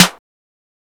MZ Snare [Southside Bape Deep].wav